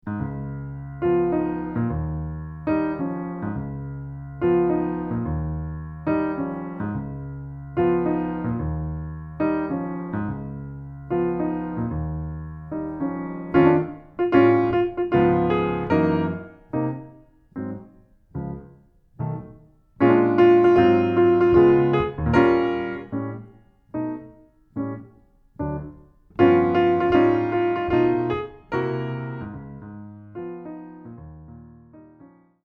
solo piano arrangement